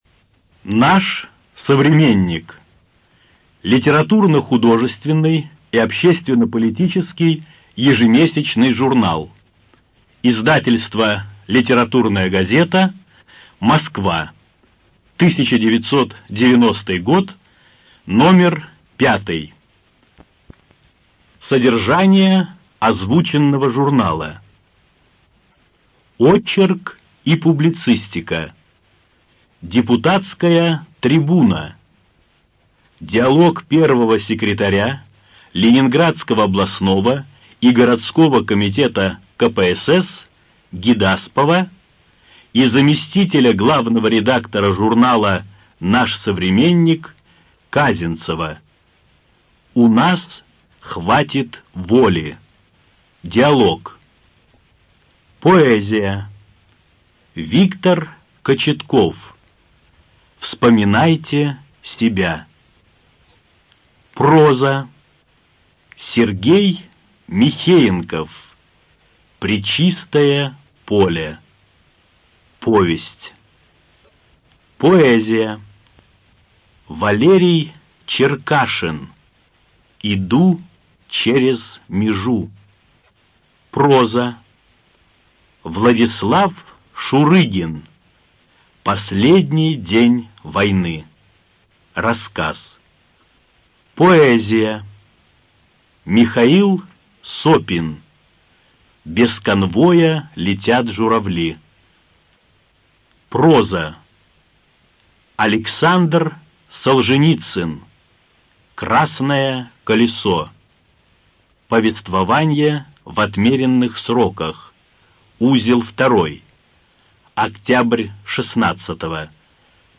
Студия звукозаписиКругозор